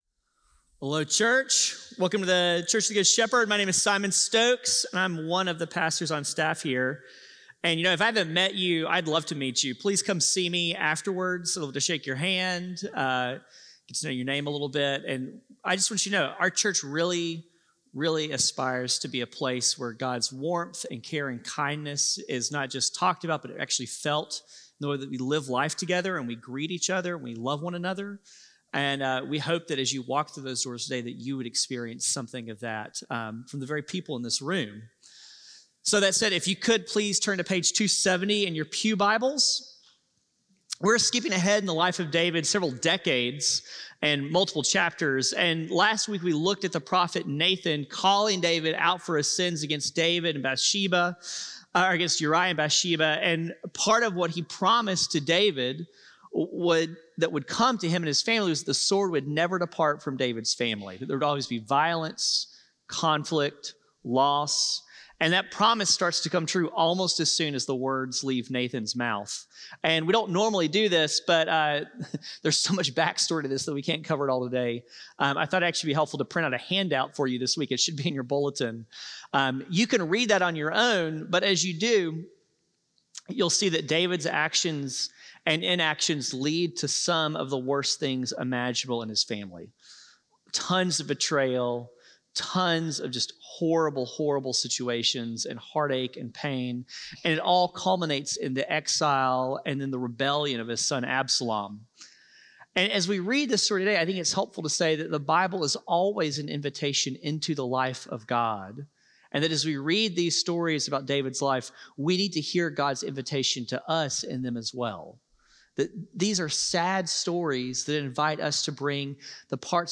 CGS-Service-5-18-25-Audio-Podcast.mp3